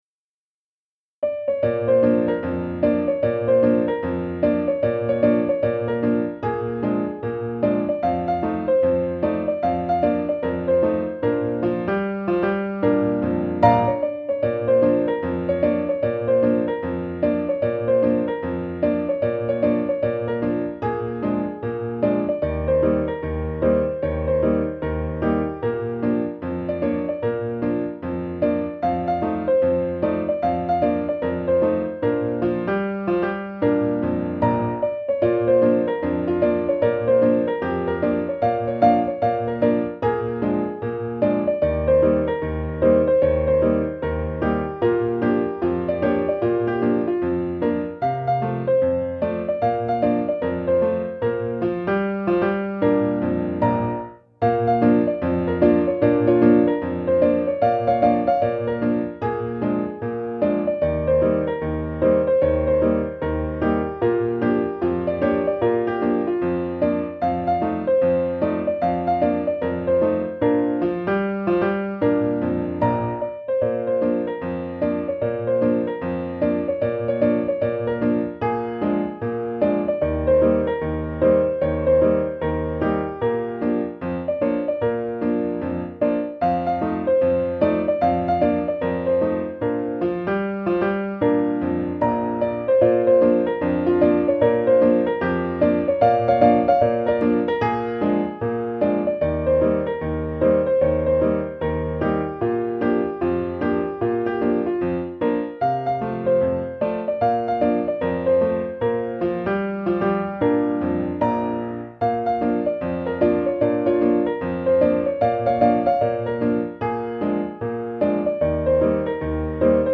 The Blues Ain't Nothin' (Leroy 'Lasses' White - 1912) One of the earliest blues songs published.